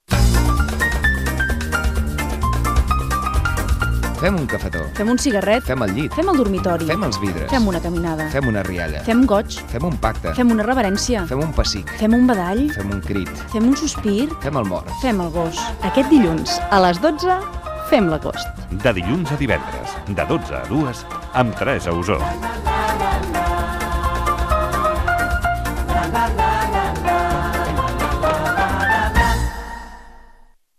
Promoció del programa
Entreteniment